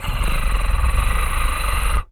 cat_purr_low_02.wav